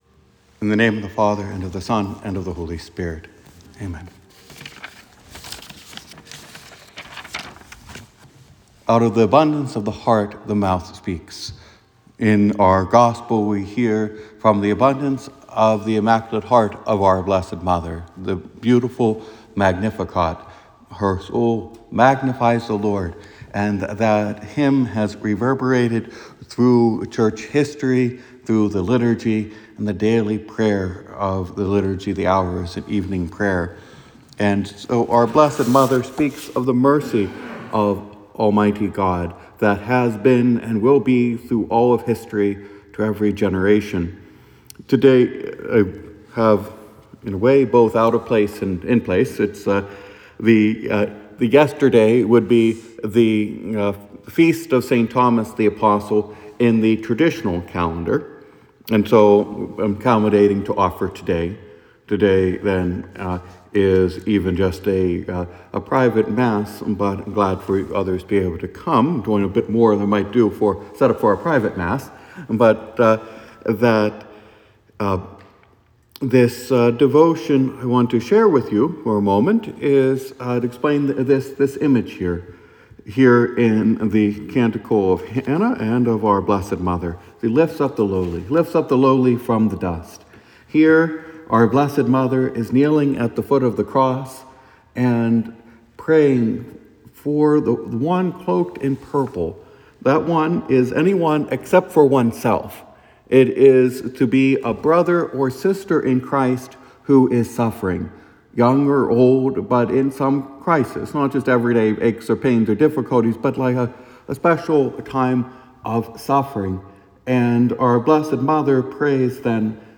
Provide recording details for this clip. mass-for-st.-thomas-on-december-22.m4a